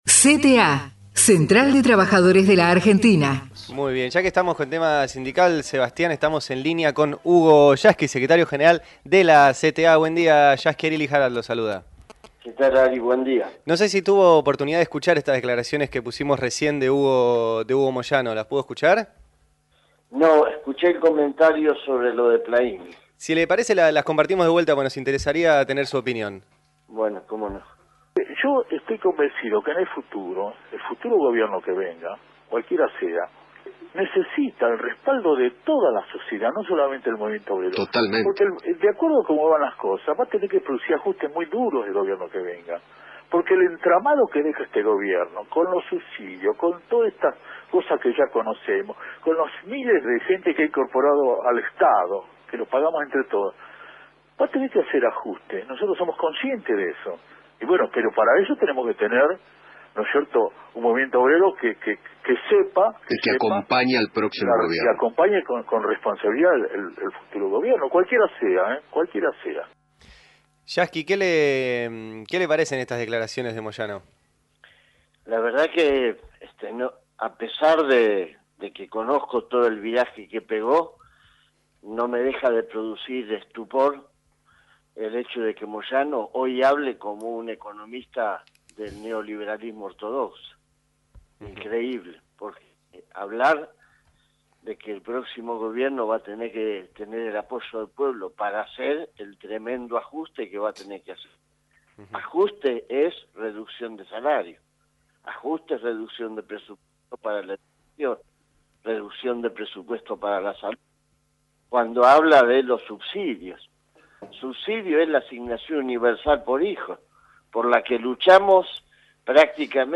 El secretario General de la Central de Trabajadores de la Argentina (entrevistado en la Radio Pública) opina sobre los dichos del camionero acerca del "ajuste" del proóximo gobierno.